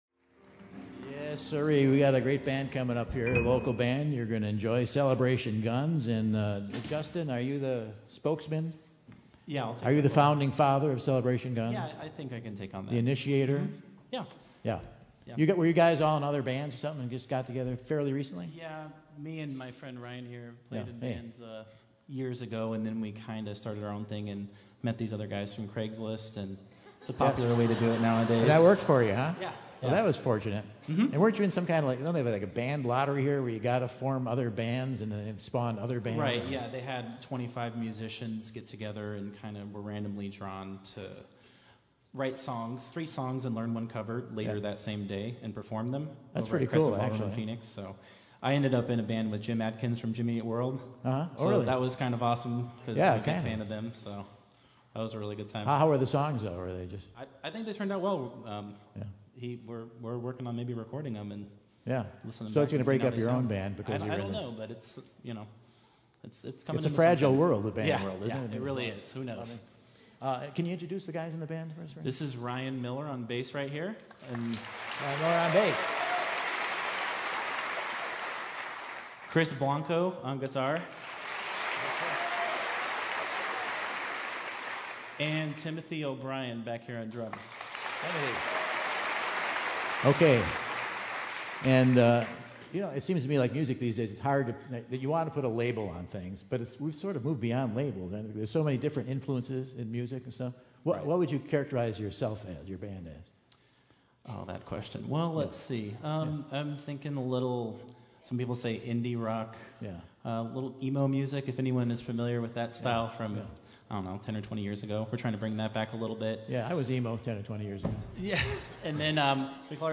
indie jungle pop rockers